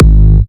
Huge Kick 014 G#.wav